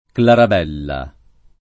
[ klarab $ lla ]